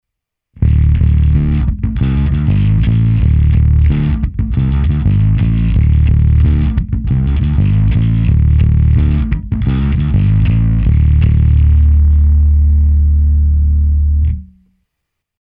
Udělal jsem nahrávky, kdy jsem použil baskytaru Music Man StingRay 5, kompresor TC Electronic SpectraComp a preamp Darkglass Alpha Omega Ultra.
Zkreslený zvuk bez simulace
Pro zajímavost, takto byl nastaven preamp Darkglass Alpha Omega Ultra (přepínače GROWL a BITE aktivní), na baskytaře basy přidané o 10%, středy přidané o 30%, výšky ubrané o 10%, paralelní zapojení cívek snímače.